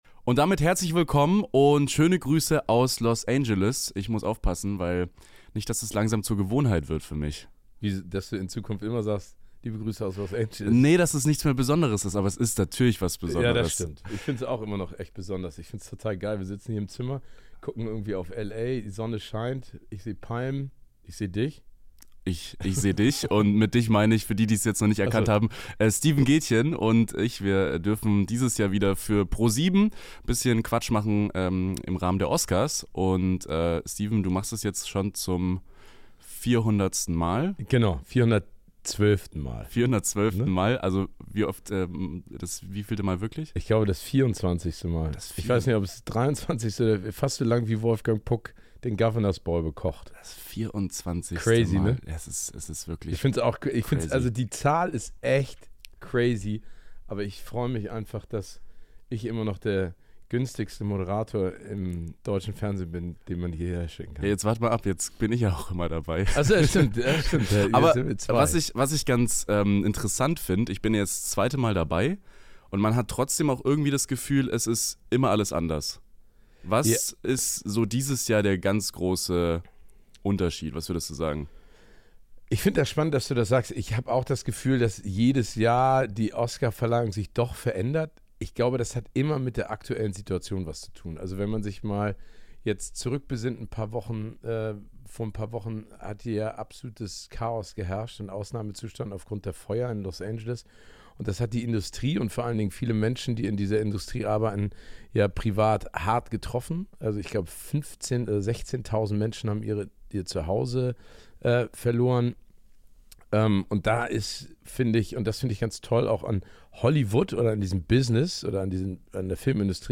Kurz vor der Verleihung der Oscars haben wir wieder Steven Gätjen zu Gast. Wie bereitet sich Steven vor und was machen die Stars eigentlich nach den Oscars?